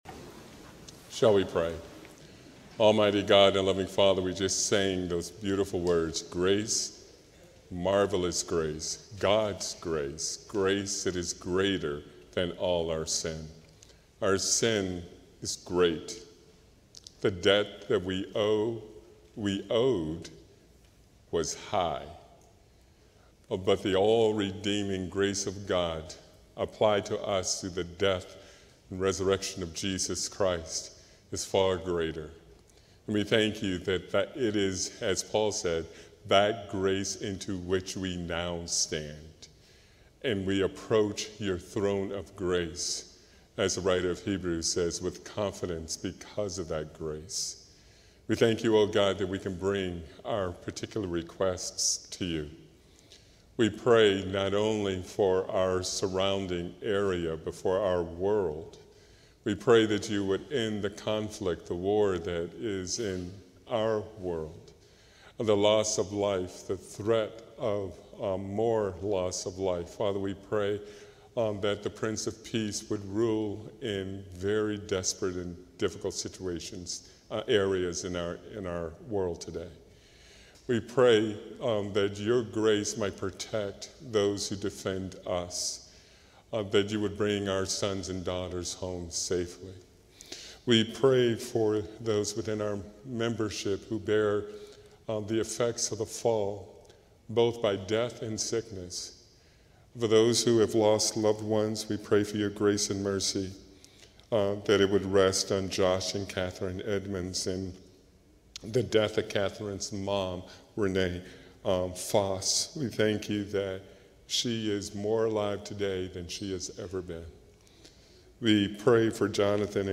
A sermon from the series "The Gospel of John."